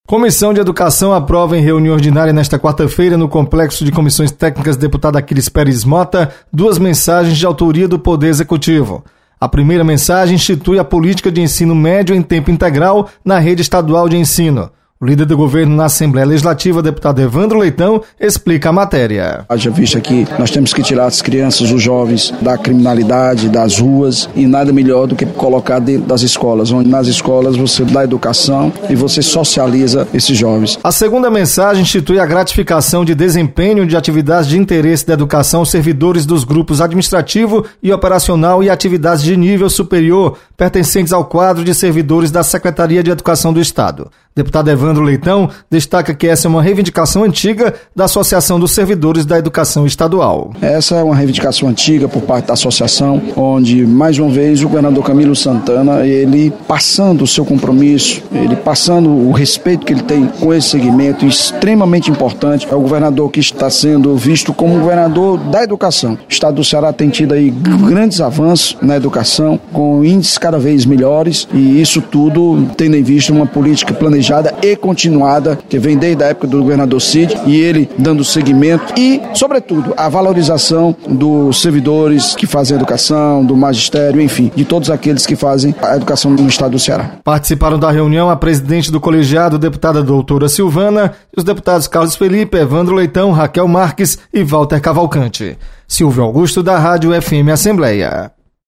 Comissão de Educação debate Política de Ensino Médio em Tempo Integral. Repórter